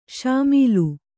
♪ śamīlu